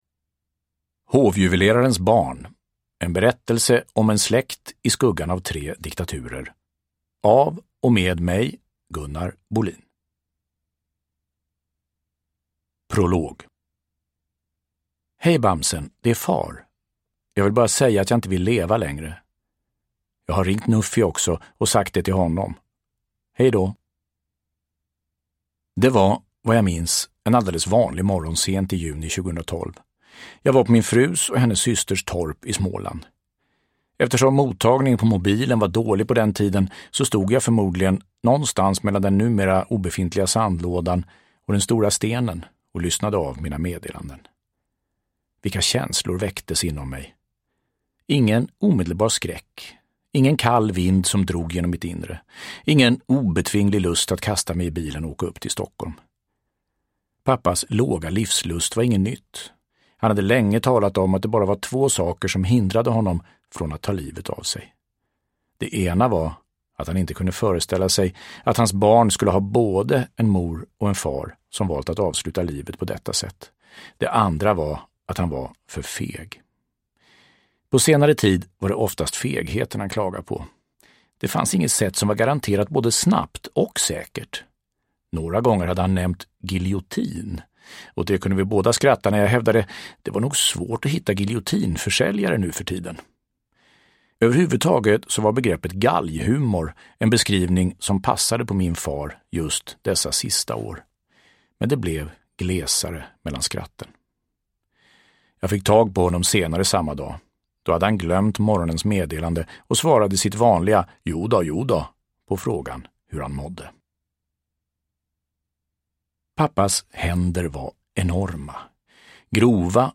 Hovjuvelerarens barn : en berättelse om en släkt i skuggan av tre diktaturer – Ljudbok – Laddas ner